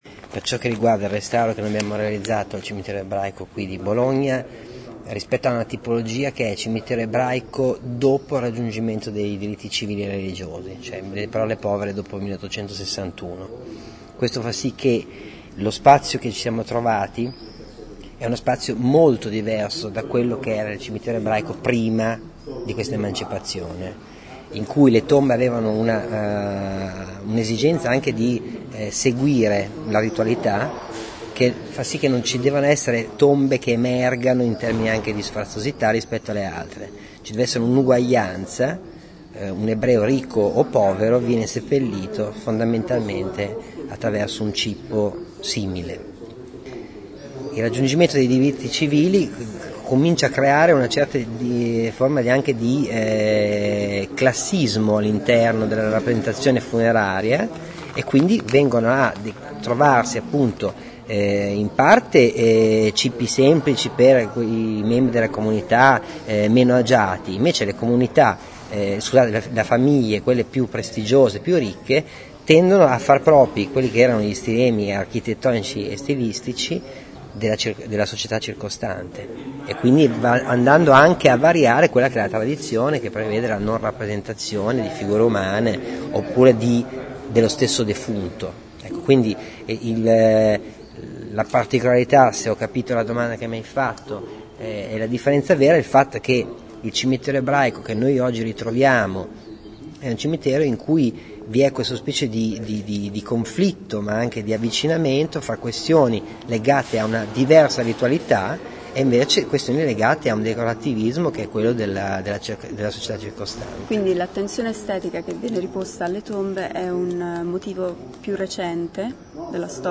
Parla uno degli architetti che hanno curato il progetto di restauro della parte ebraica della certosa a Bologna.